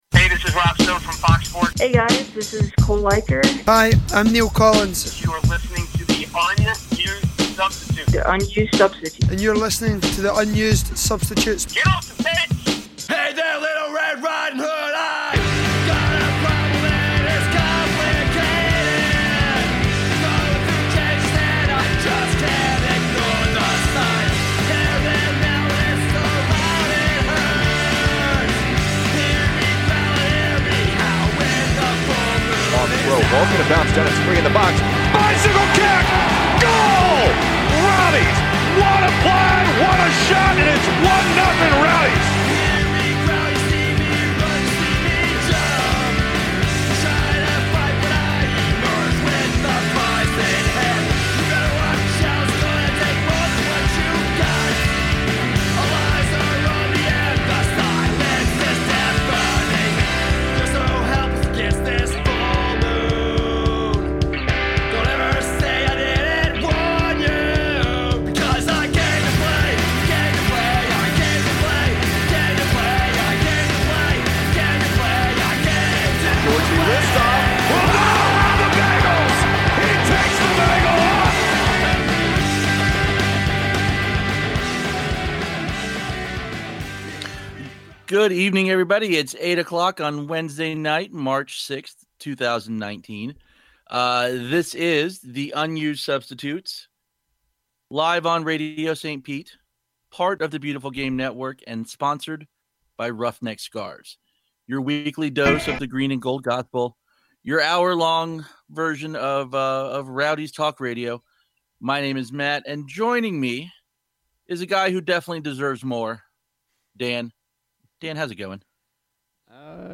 An independent, supporter-created podcast delivering news, interviews and opinions about the Tampa Bay Rowdies soccer club, playing in the USL. Airs live on Radio St. Pete and a member of the Beautiful Game Network.